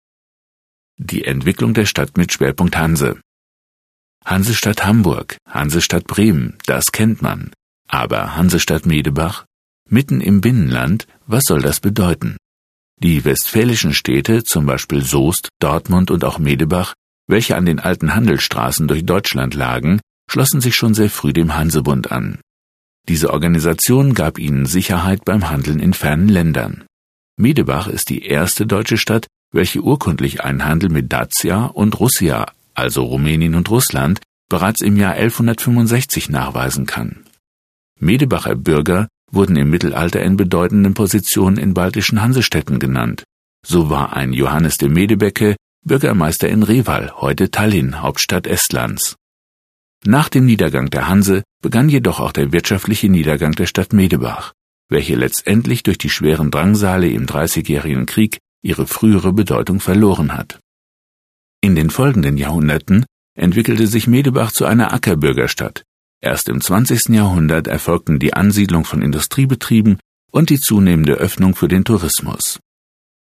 Hör-Gudie für den Historischen Stadtrundgang Medebach